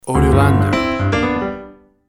Music logo, Piano jazz Fill.
WAV Sample Rate 16-Bit Stereo, 44.1 kHz
Tempo (BPM) 150